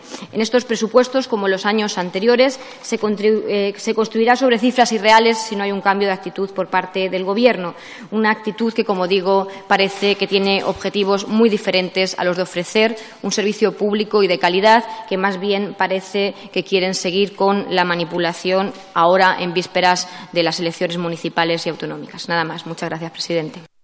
Fragmento de la intervención de Isabel Rodríguez en la Comisión mixta para el control de RTVE del 23/09/2014. Presupuestos de RTVE